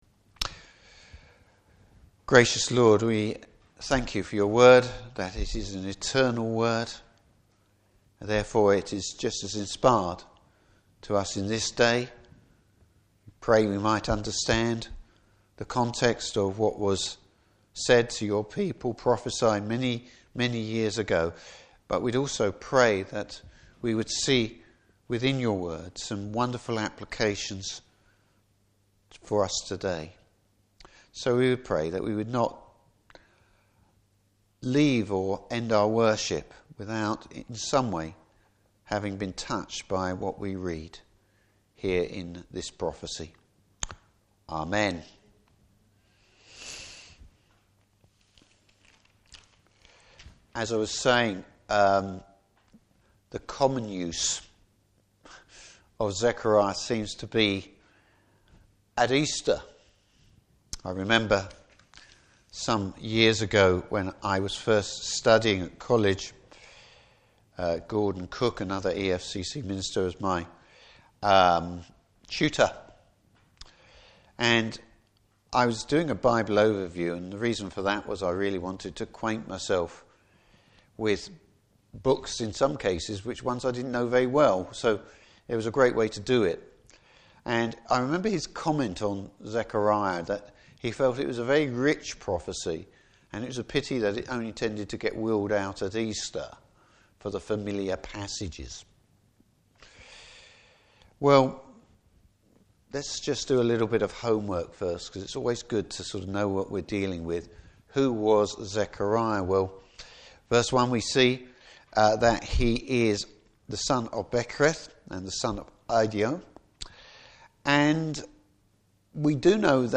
Service Type: Evening Service Israel is challenged to be whole hearted in the Lord’s service.